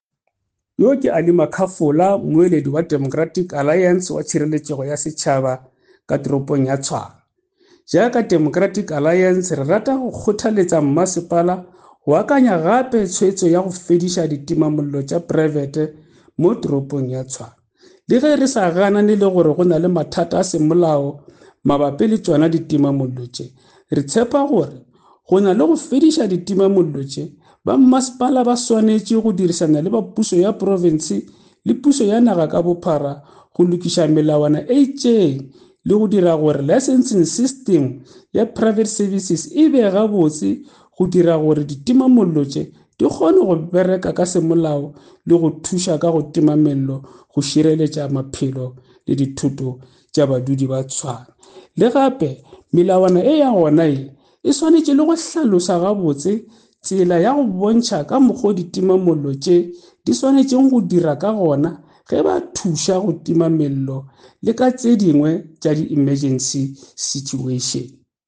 Note to Editors: Please find English and Setswana soundbites by Cllr Alfred Makhafula